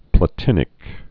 (plə-tĭnĭk)